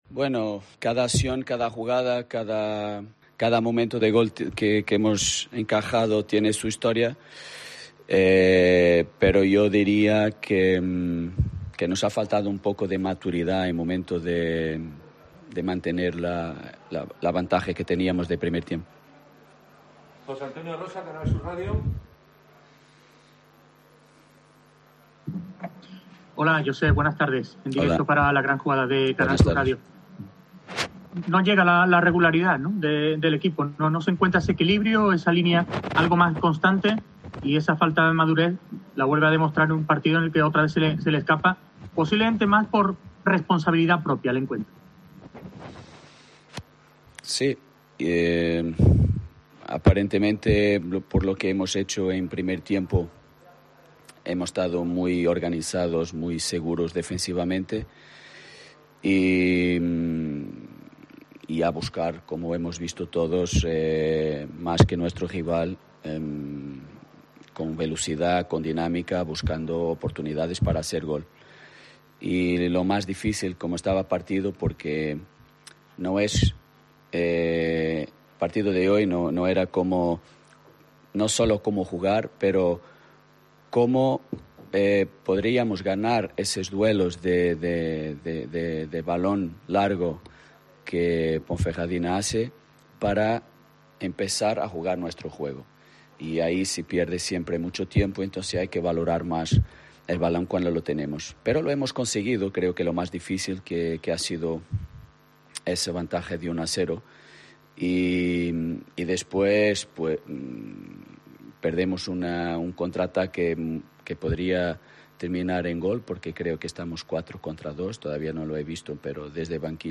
Postpartido Ponferradina - Almería (2-1)